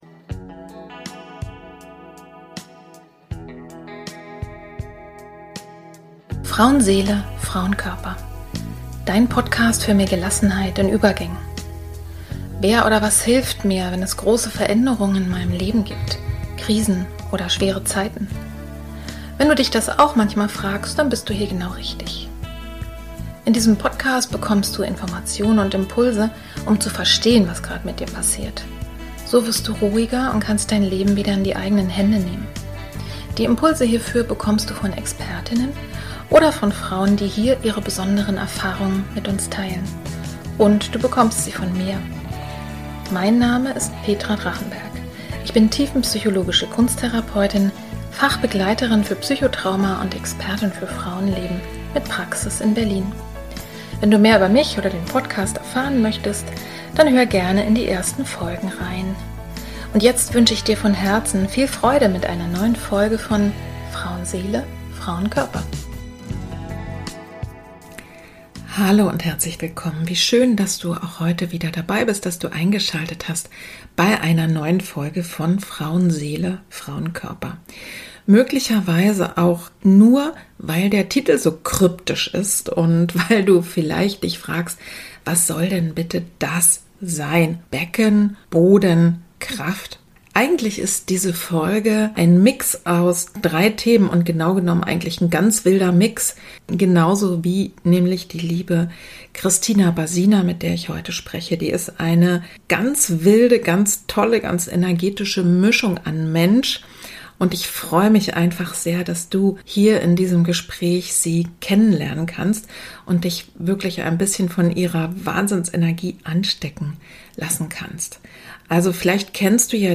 Ich hätte diese Folge auch anders nennen können, z.B. „In Verbindung kommen“, denn das zieht sich wie ein roter Faden durch dieses Gespräch: In Verbindung zu meiner „Berufung“- in Verbindung zu meinem Körper und in Verbindung zu meinen Entscheidungen und...